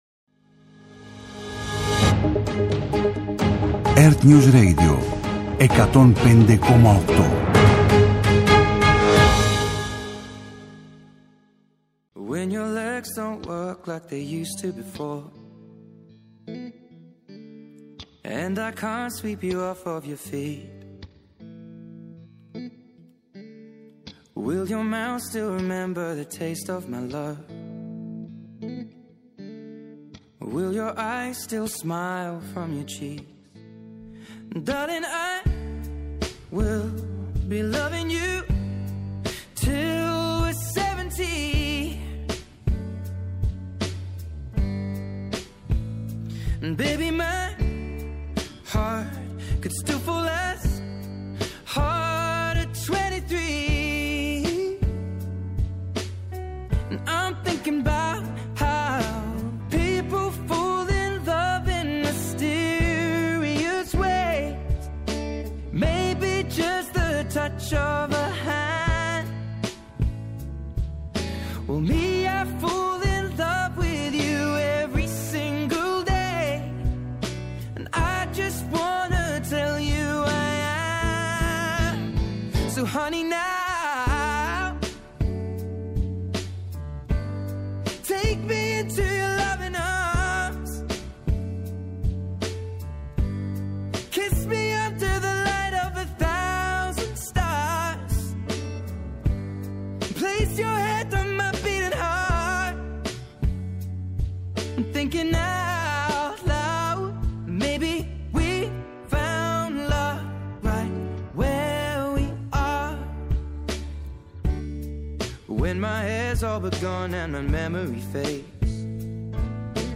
-Απόσπασμα από την ενημέρωση των πολιτικών συντακτών από τον κυβερνητικό εκπρόσωπο, Παύλο Μαρινάκη